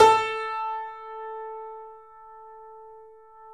Index of /90_sSampleCDs/E-MU Producer Series Vol. 5 – 3-D Audio Collection/3D Pianos/YamaHardVF04